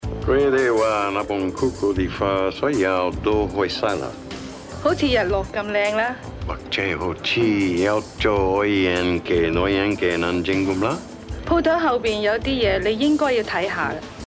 Phelps gets his message in a Chinese curio shop in San Francisco's "Old Chinatown." He even speaks Chinese to the owner!
phelps-chinese.mp3